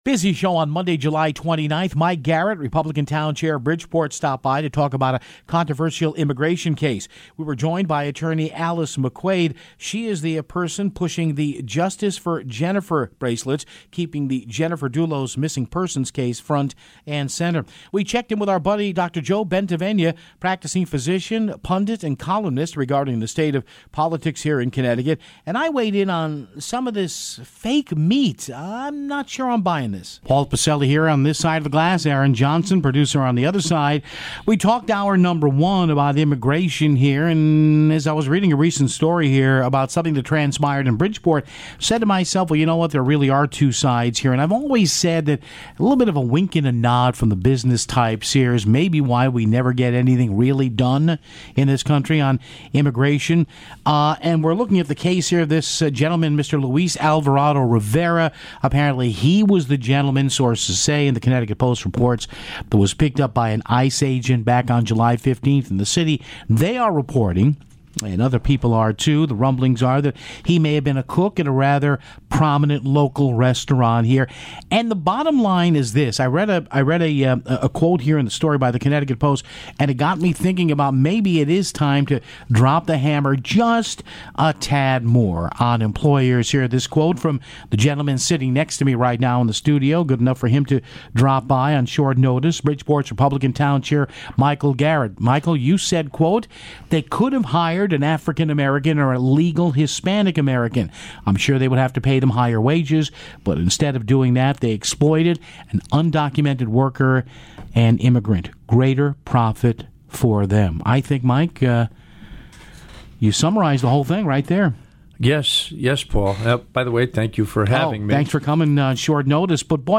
in-studio to discuss his comments in a recent article about the employers of undocumented people.
calls in to discuss the latest in Connecticut politics.